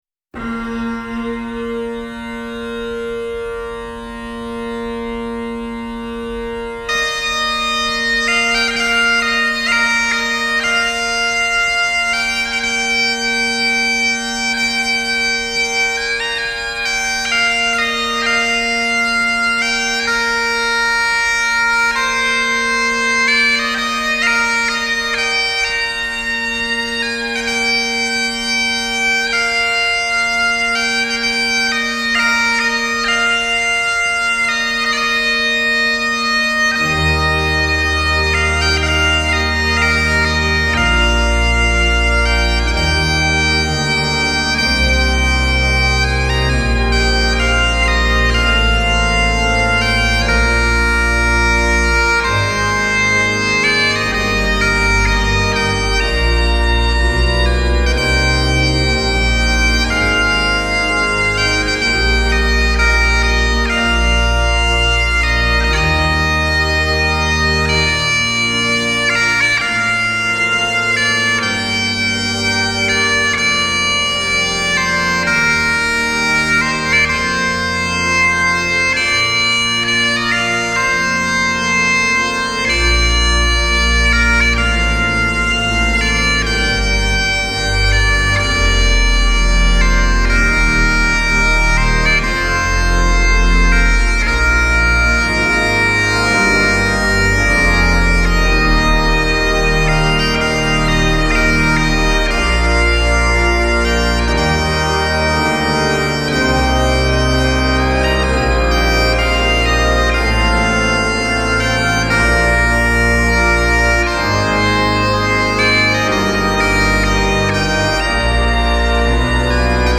Highland pipes
the big organ